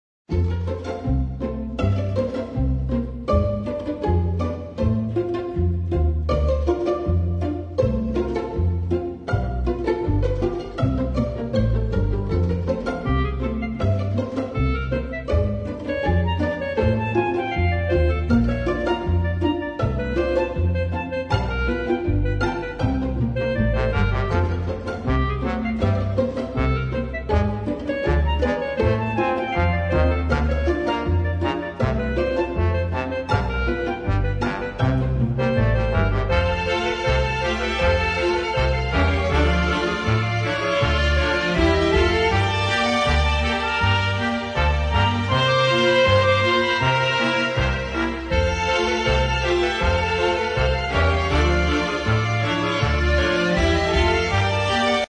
Класична і Опера (81)
Фантазії на теми пісень